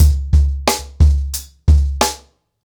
TrackBack-90BPM.37.wav